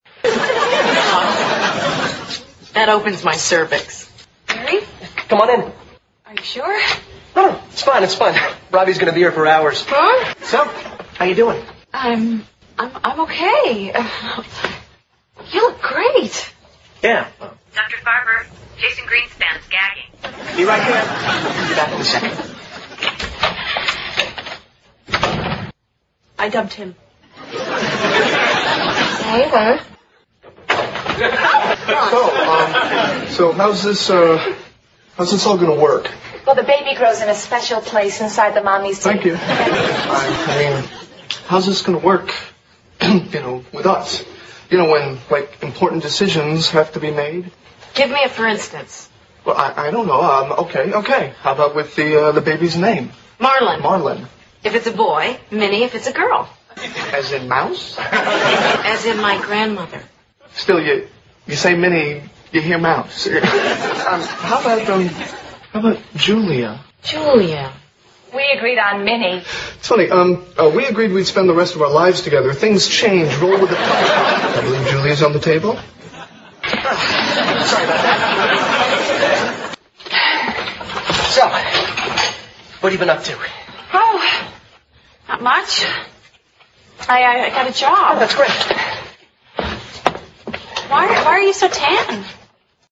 在线英语听力室老友记精校版第1季 第20期:参加助产培训班(8)的听力文件下载, 《老友记精校版》是美国乃至全世界最受欢迎的情景喜剧，一共拍摄了10季，以其幽默的对白和与现实生活的贴近吸引了无数的观众，精校版栏目搭配高音质音频与同步双语字幕，是练习提升英语听力水平，积累英语知识的好帮手。